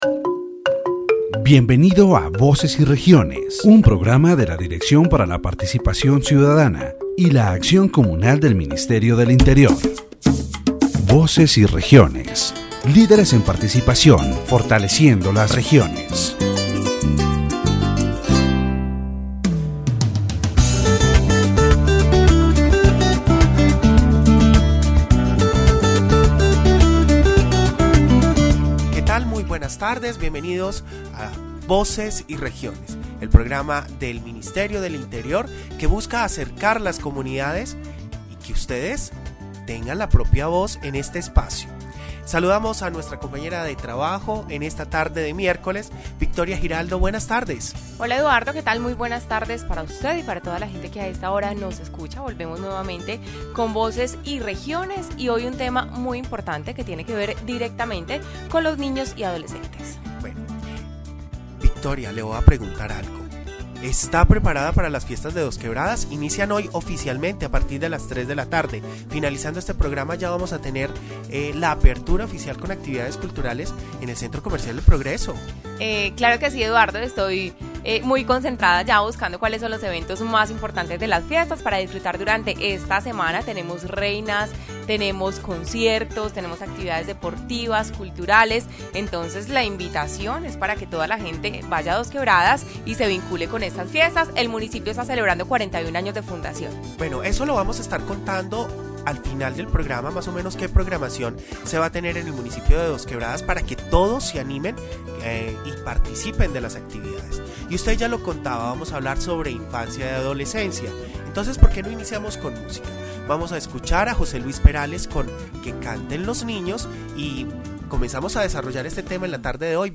The radio program "Voices and Regions" of the Directorate for Citizen Participation and Community Action of the Ministry of the Interior, in its thirteenth episode, focuses on comprehensive care for early childhood and adolescence.